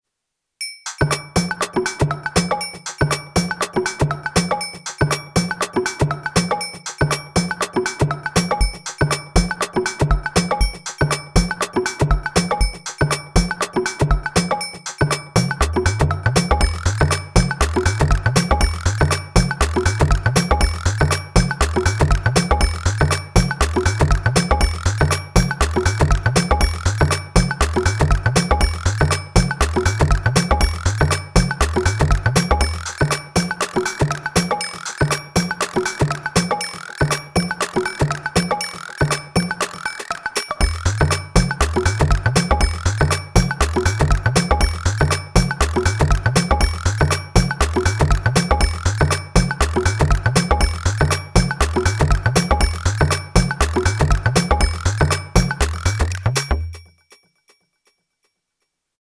Here’s some more Syntakt ‘world music’. The main challenge was trying to synthesize a somewhat convincing Guiro.